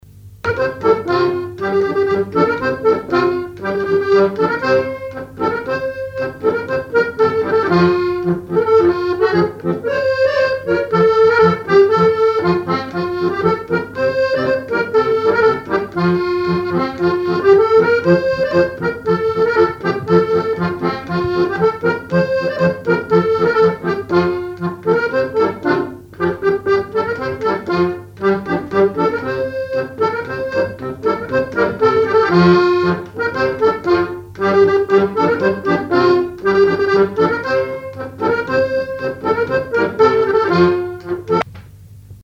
Garnache (La)
danse : polka des bébés ou badoise
accordéon chromatique
Pièce musicale inédite